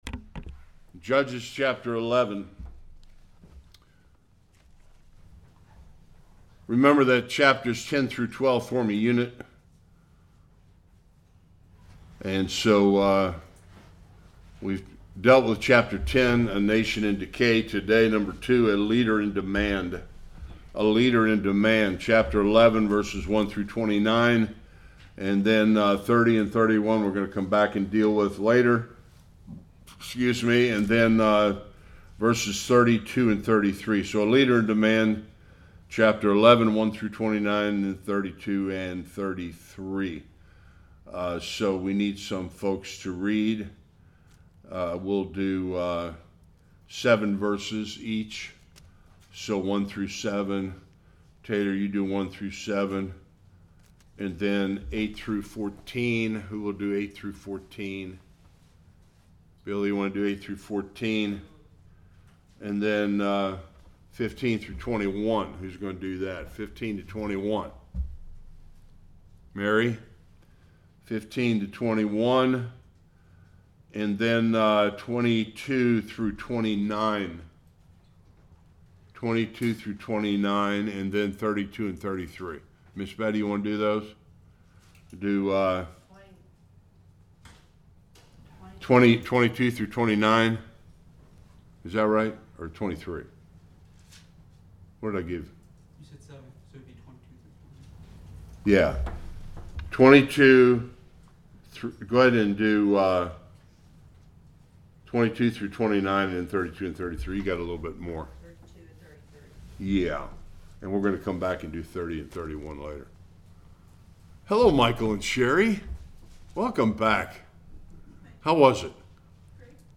Sunday School God raises up Jephthah